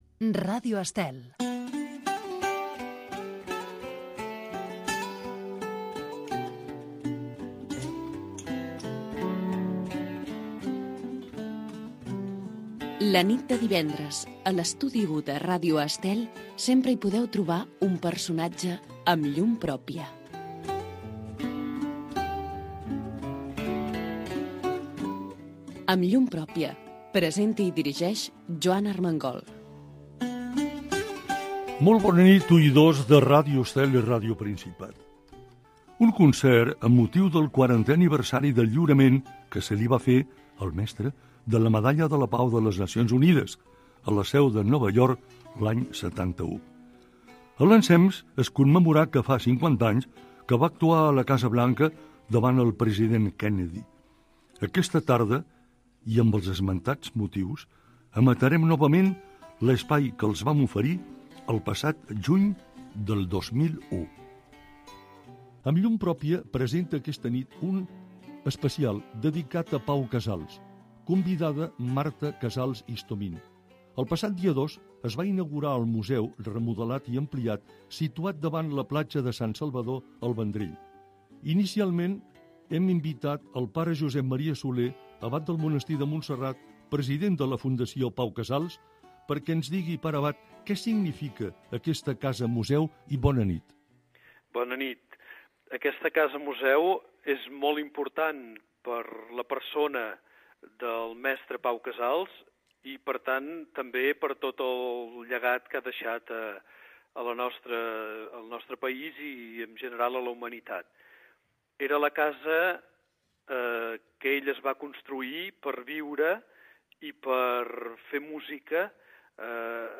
Careta del programa, presentació de l'espai dedicat a Pau Casals que es va emetre l'any 2001 amb motiu de la inauguració de la Casa Museu Pau Casals, amb declaracions de l'abat del Monestir de Montserrat Josep Maria Solé, fragment d'un espai emès per TVE 2
Divulgació